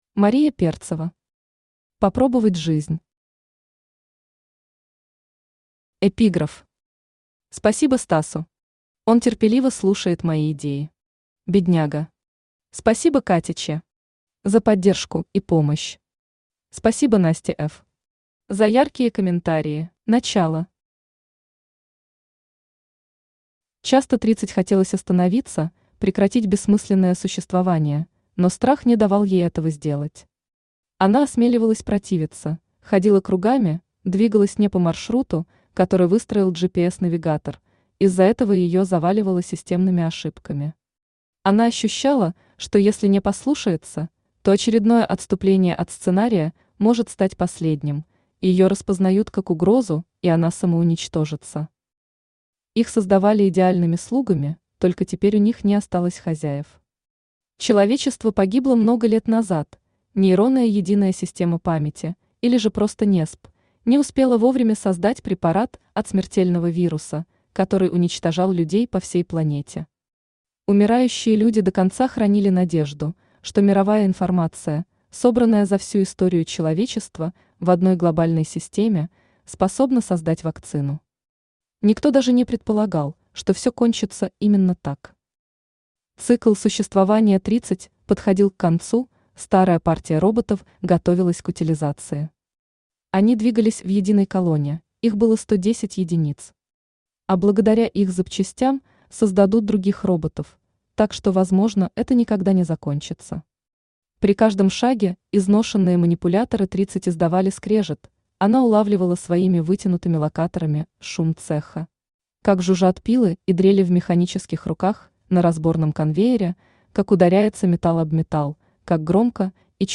Аудиокнига Попробовать Жизнь | Библиотека аудиокниг
Aудиокнига Попробовать Жизнь Автор Мария А Перцева Читает аудиокнигу Авточтец ЛитРес.